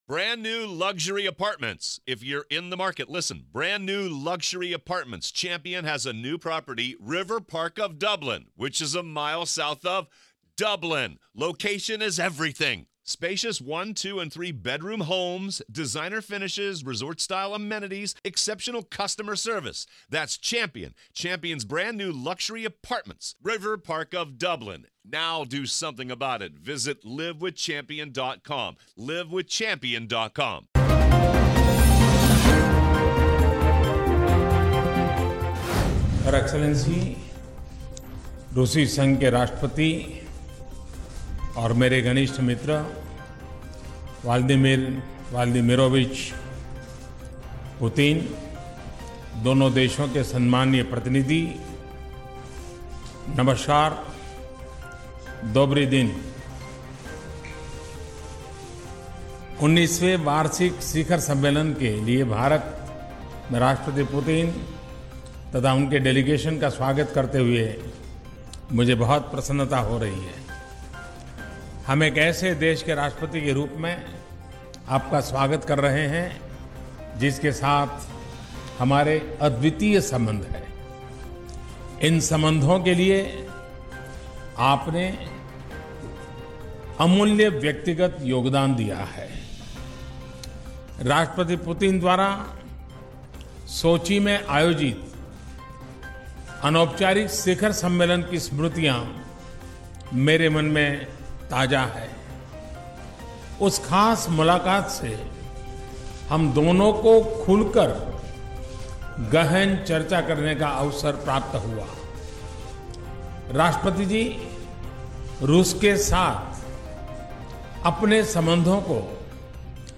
न्यूज़ रिपोर्ट - News Report Hindi / मोदी और पुतिन की दोस्ती लायी रंग, भारत को मिलेगा S-400